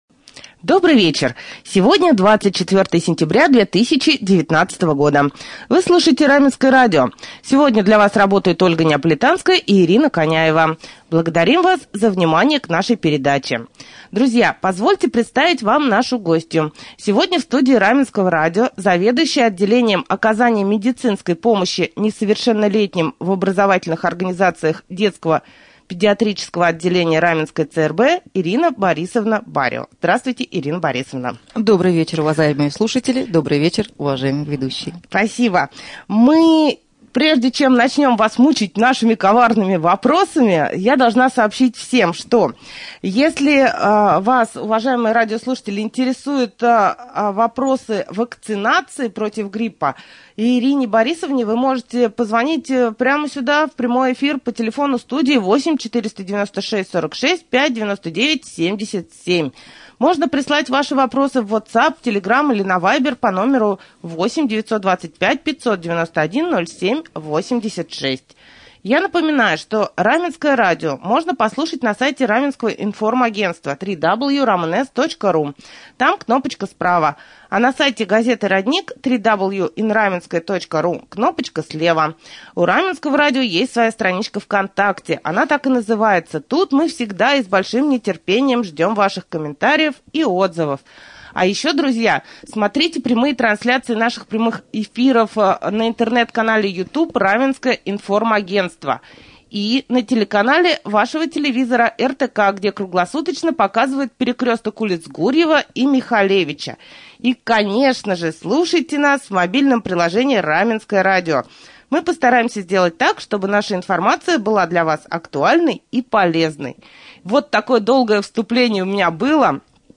И многое другое узнаете из прямого эфира